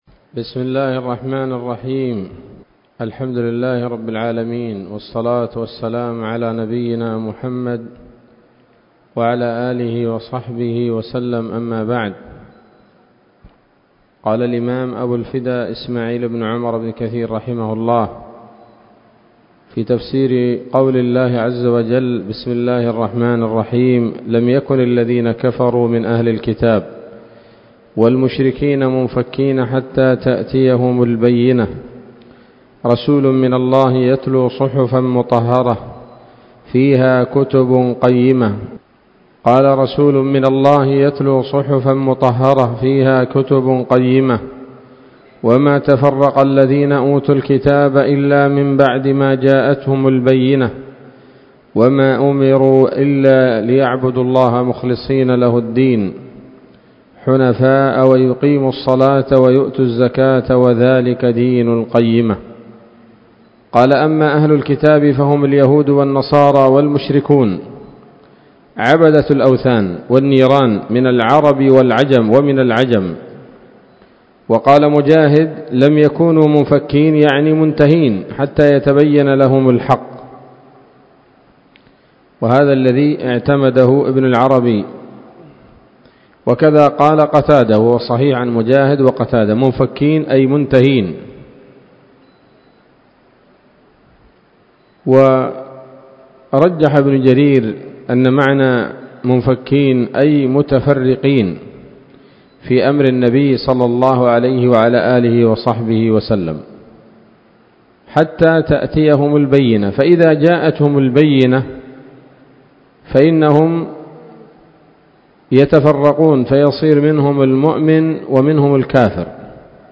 الدرس الثاني من سورة البينة من تفسير ابن كثير رحمه الله تعالى